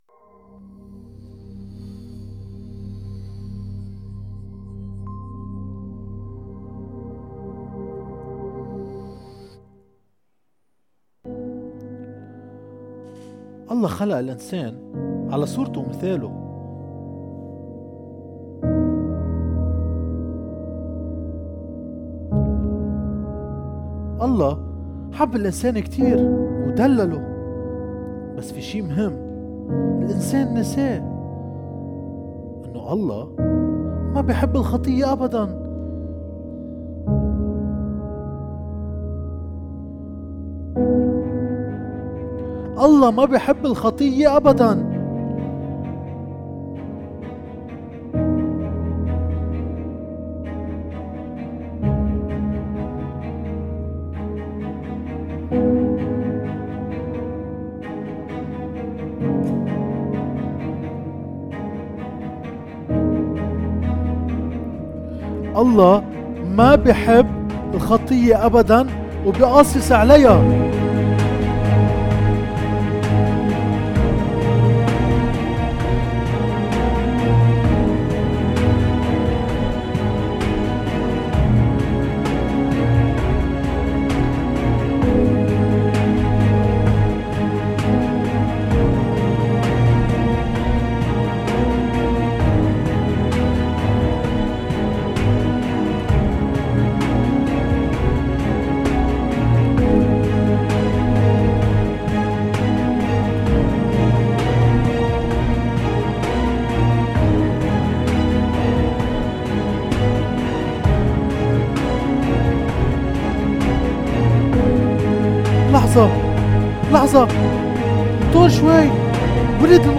تمثيليّة